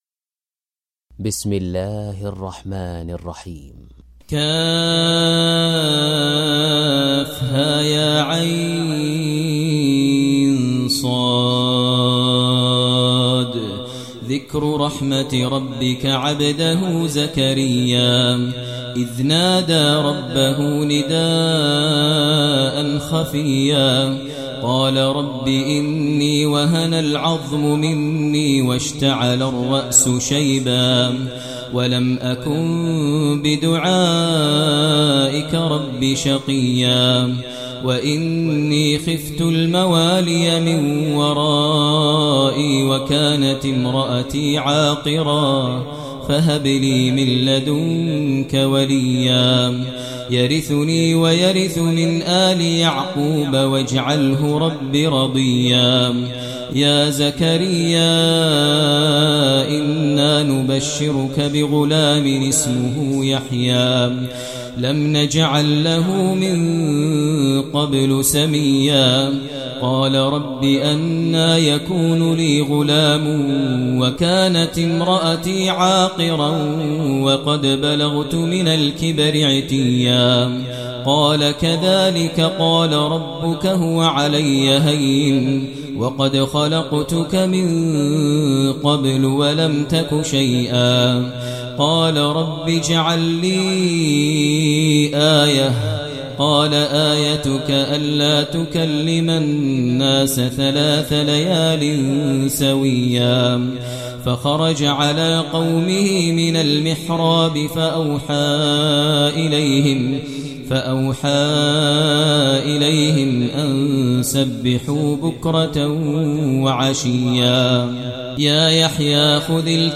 Surah Maryam Recitation Maher al Mueaqly
Surah Maryam, listen online mp3 tilawat / recitation in Arabic recited by Imamm e Kaaba Sheikh Maher Mueaqly.